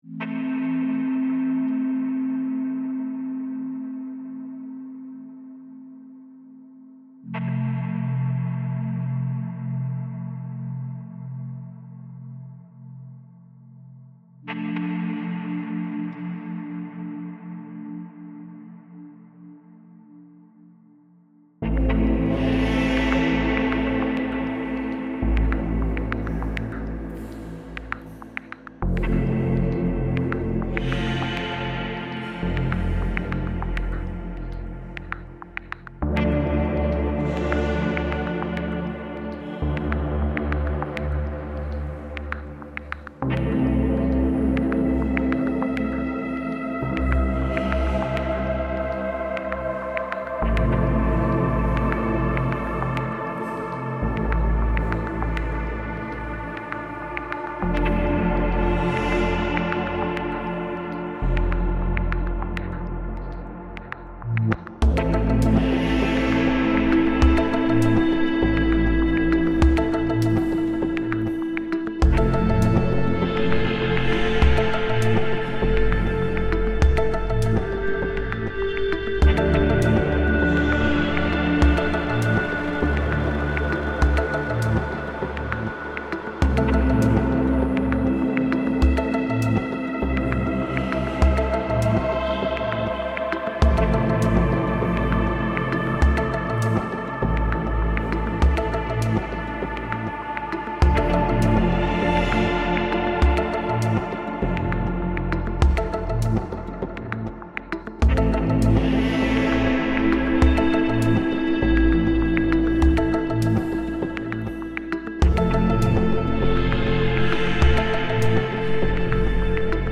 Finnish sheep reimagined